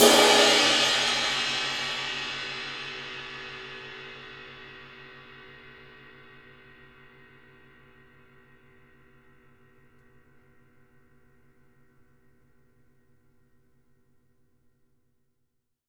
Index of /90_sSampleCDs/Sampleheads - New York City Drumworks VOL-1/Partition A/KD RIDES
CRASH     -L.wav